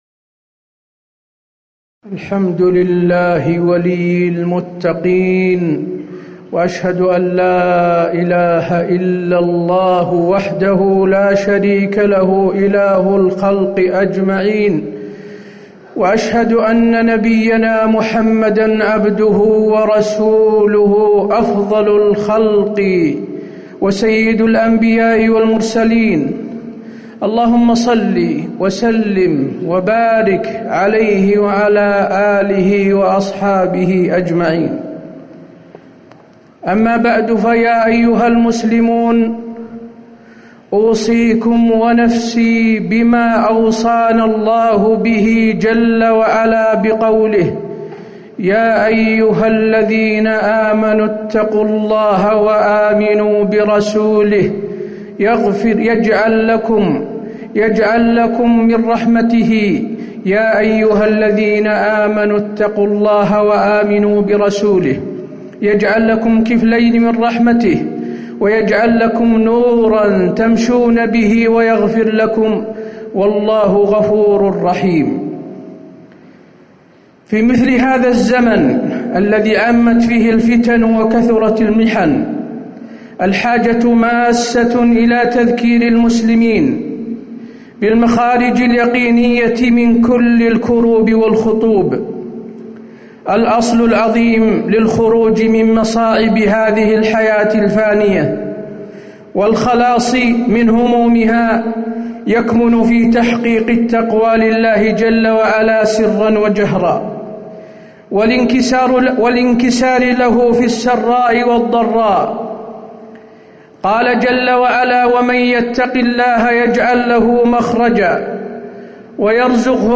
تاريخ النشر ١٧ صفر ١٤٤٠ هـ المكان: المسجد النبوي الشيخ: فضيلة الشيخ د. حسين بن عبدالعزيز آل الشيخ فضيلة الشيخ د. حسين بن عبدالعزيز آل الشيخ الحرص على الإلتجاء إلى الله في كل حال